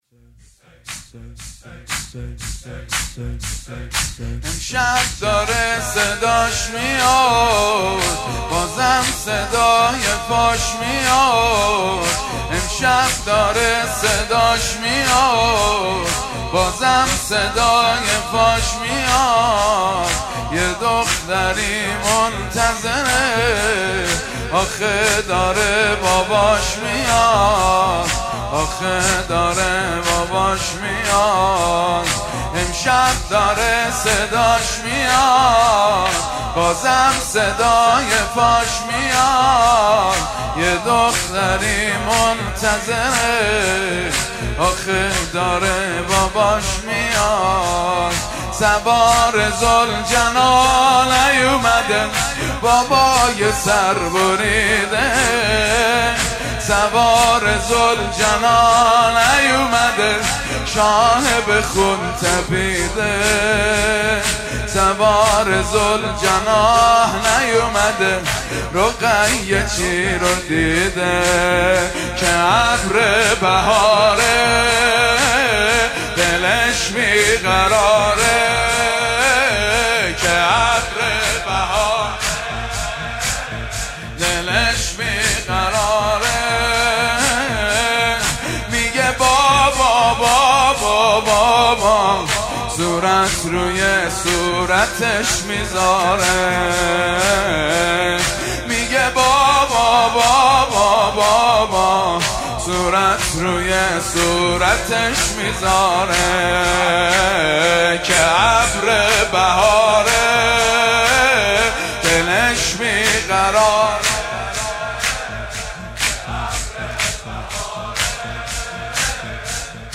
مناسبت : دهه اول صفر
قالب : زمینه